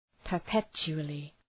Προφορά
{pər’petʃu:əlı}